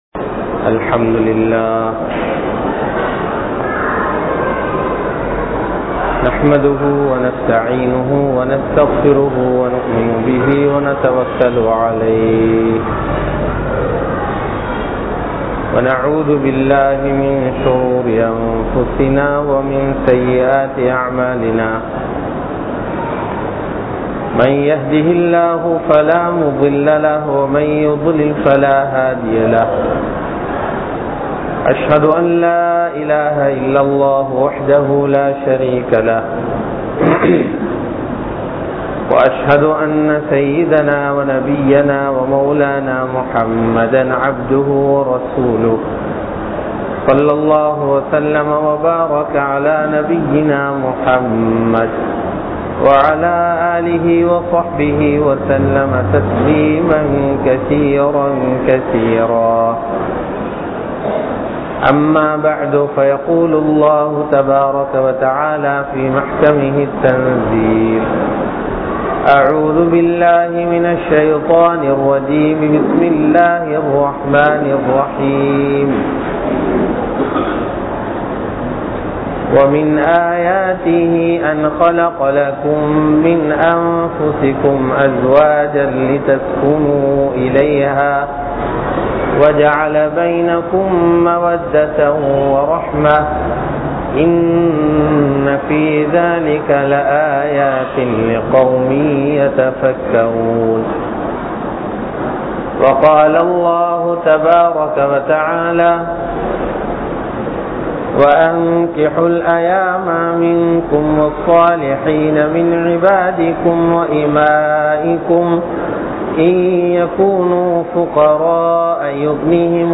Maranaththudan Mudivathillai Nikkah (மரணத்துடன் முடிவதில்லை நிக்காஹ்) | Audio Bayans | All Ceylon Muslim Youth Community | Addalaichenai
Khilir Masjidh